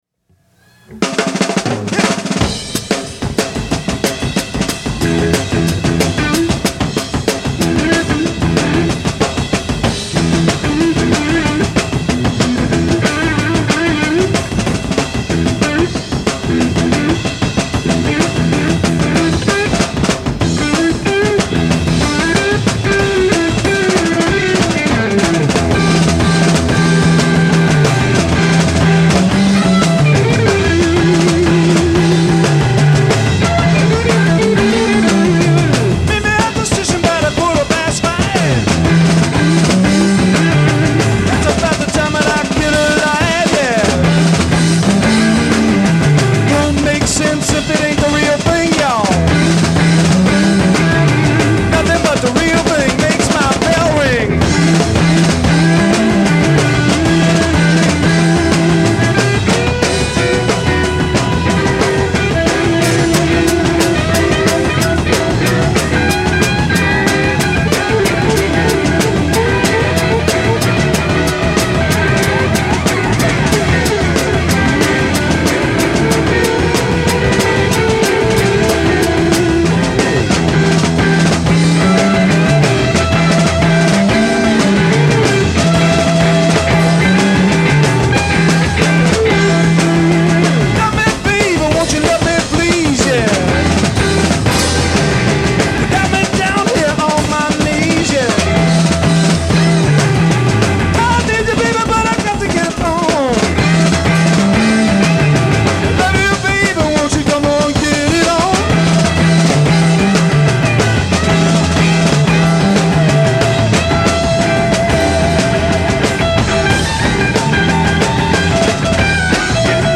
Live - Sacramento, CA